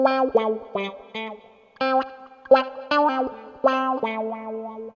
134 GTR 2 -R.wav